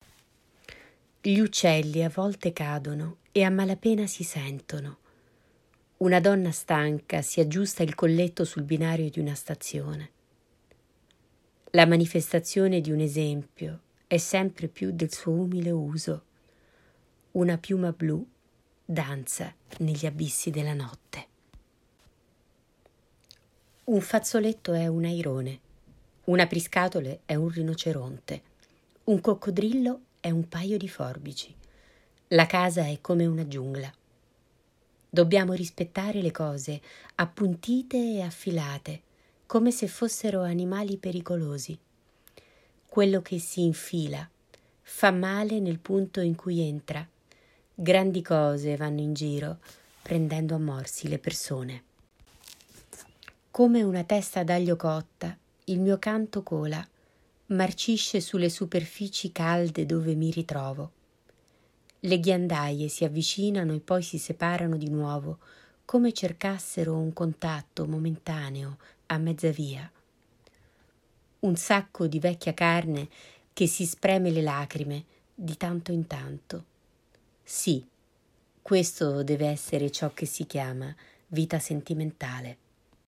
Lettura in italiano